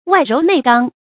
外柔内刚 wài róu nèi gāng
外柔内刚发音